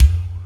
Kick 16.wav